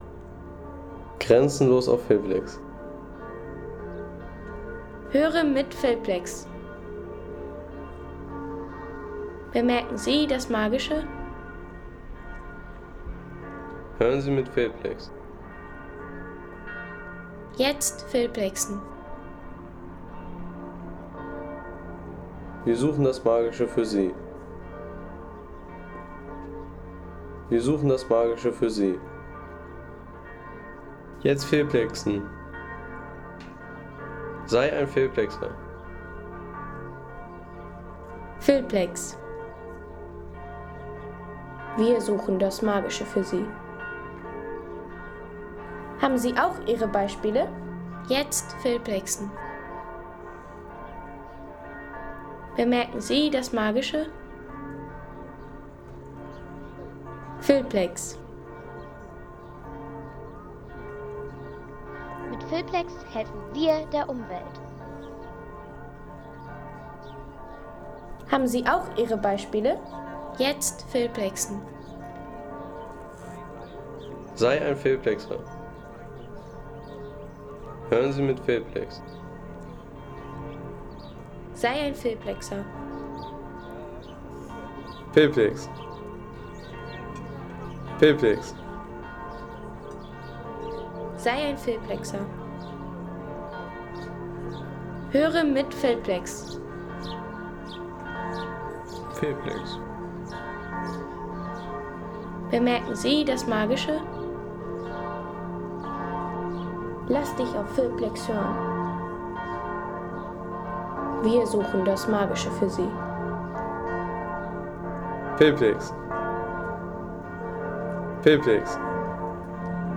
Stadtrundgang in Dresden
Stadtrundgang von der Brühlschen Terrasse zur Frauenkirche.